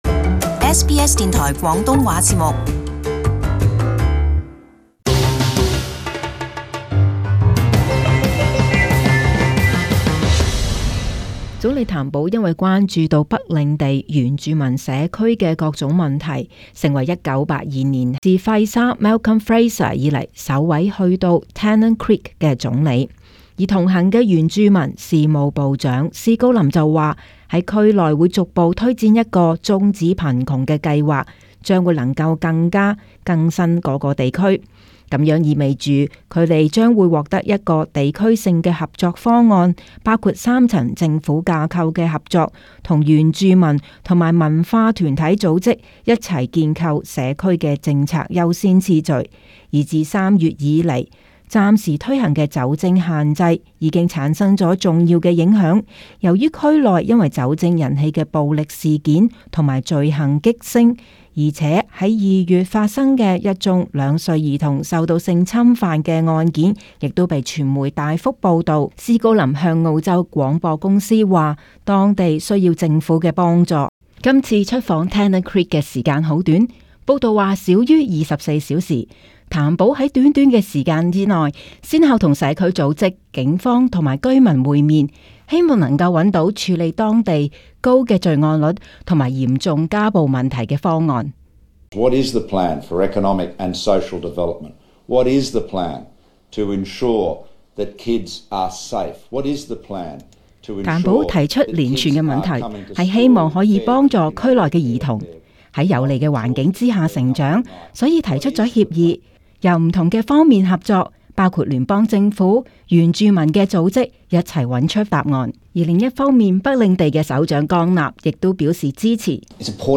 【時事報導】北領地Tennant Creek獲撥款發展